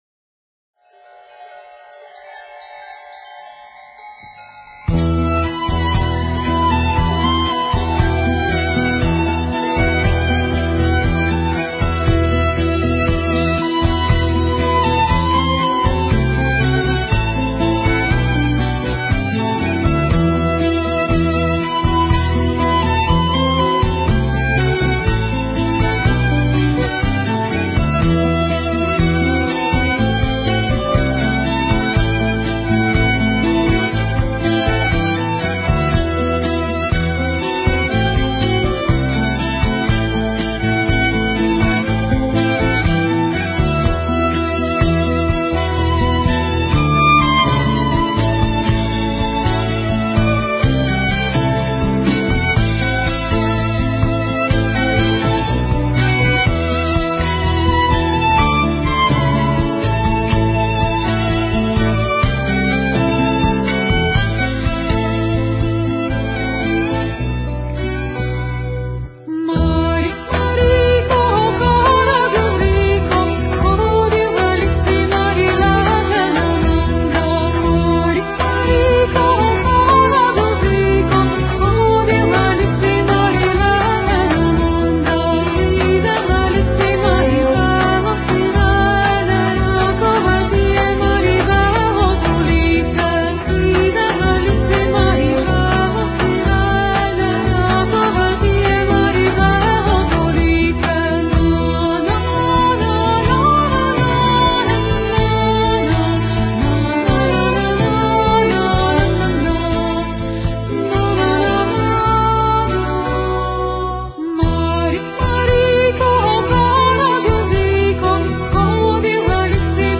Български етнофюжън ще звучи
Музикалната уърлд-фюжън група